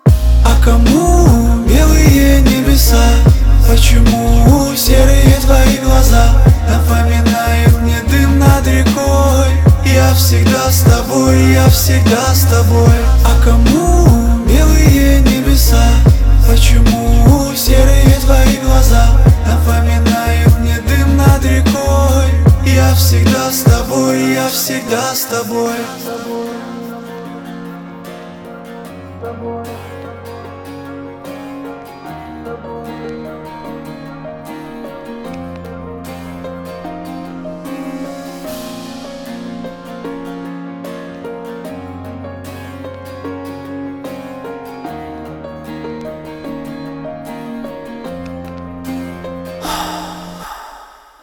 • Качество: 320, Stereo
гитара
мужской вокал
грустные
русский рэп
спокойные
лиричные